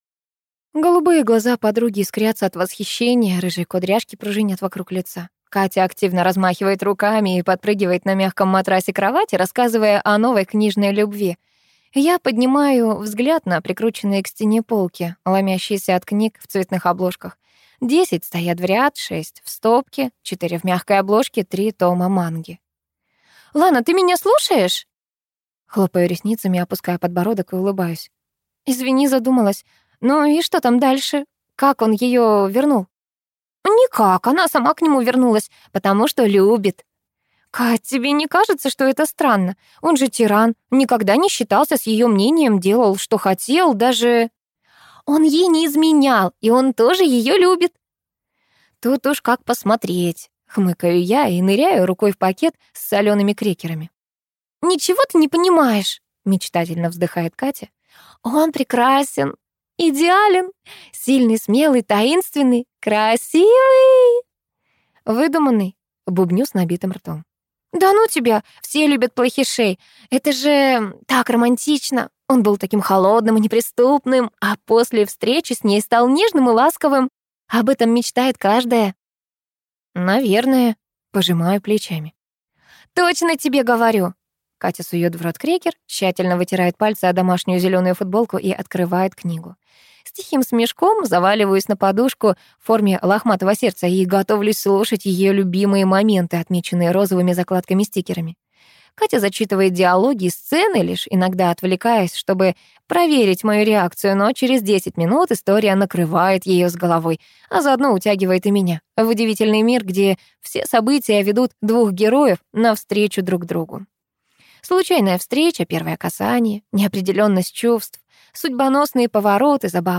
Аудиокнига Метод книжной героини | Библиотека аудиокниг